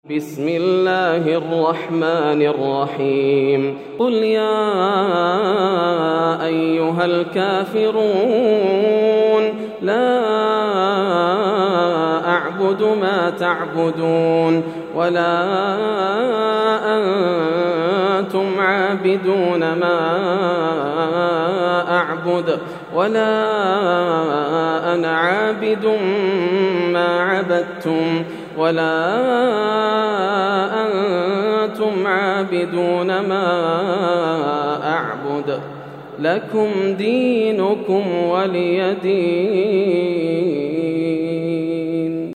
سورة الكافرون > السور المكتملة > رمضان 1431هـ > التراويح - تلاوات ياسر الدوسري